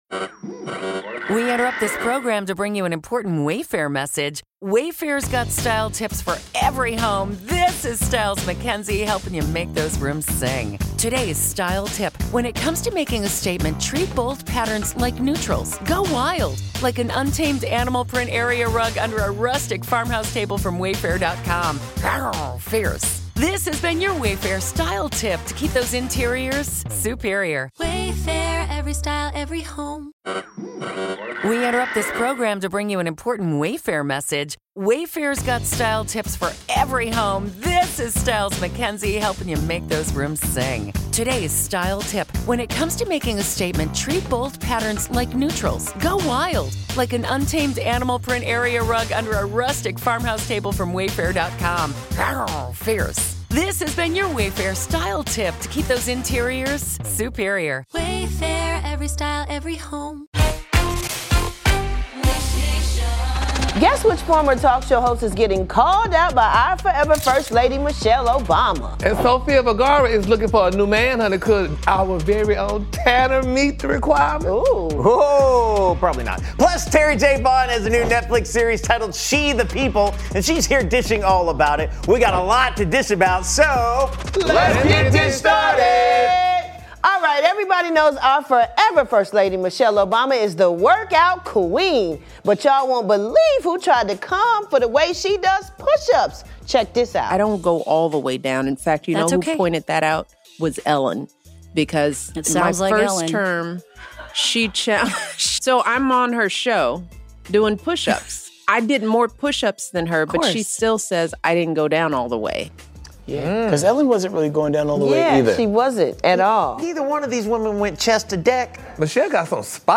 Sofia Vergara is looking for a rich man and Larsa Pippen blames COVID for her last relationship! Plus, actress Terri J. Vaughn is in studio dishin' about her new comedy series on today's Dish Nation!